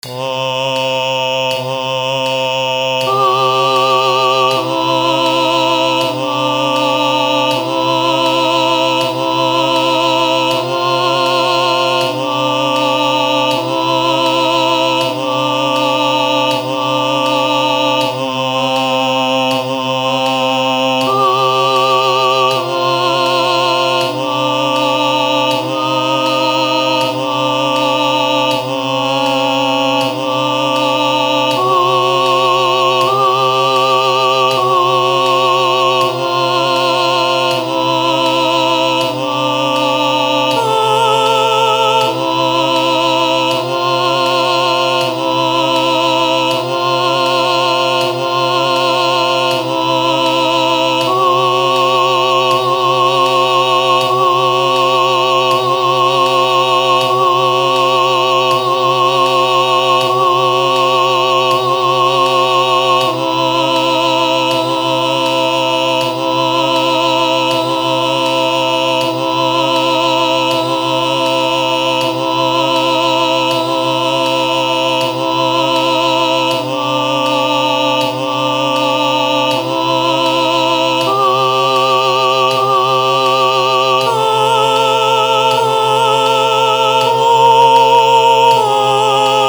I’ve included files with a drone of the key center.
Soprano Single Melody with Drone
MP3 with Soprano Voice and Cymbal